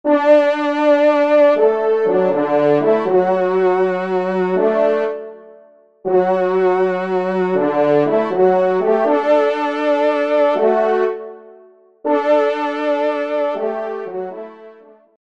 Genre : Fantaisie Liturgique pour quatre trompes
Pupitre 3ème Trompe